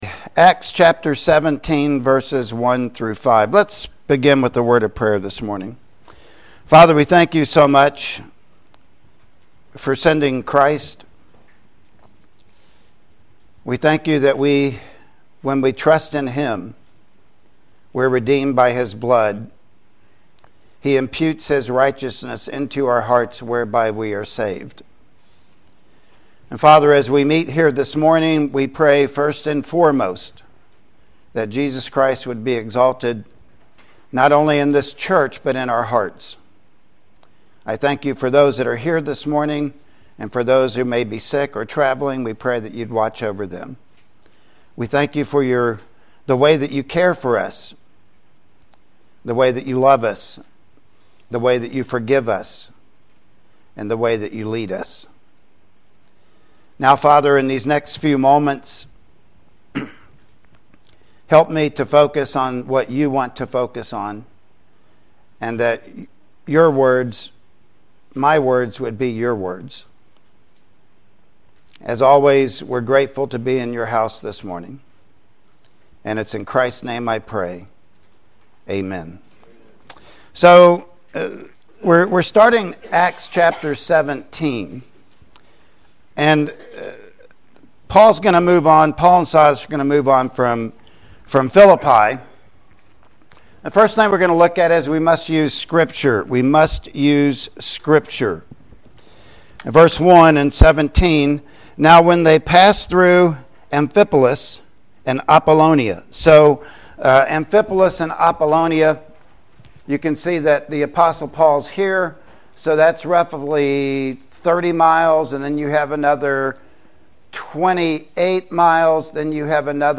Acts 17:1-5 Service Type: Sunday Morning Worship Service Topics